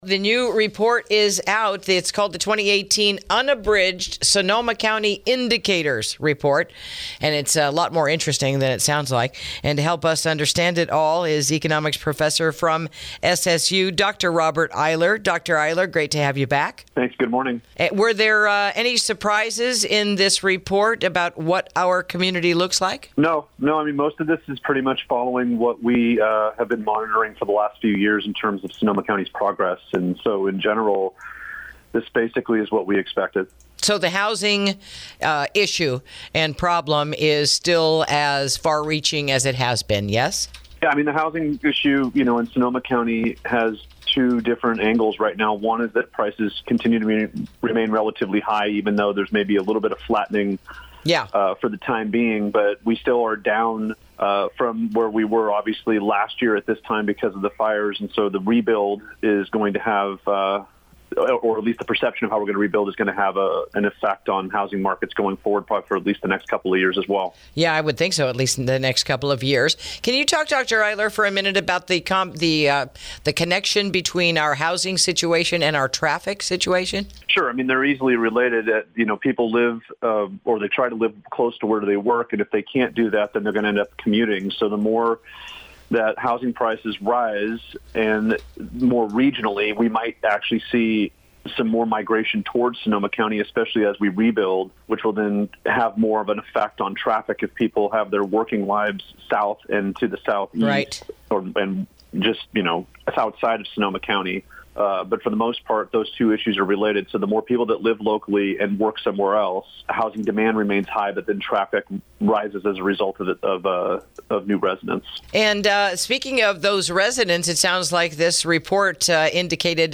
INTERVIEW: Breaking Down the Recently Released 2018 Unabridged Sonoma County Indicators Report